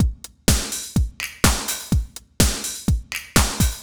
Index of /musicradar/retro-house-samples/Drum Loops
Beat 08 Full (125BPM).wav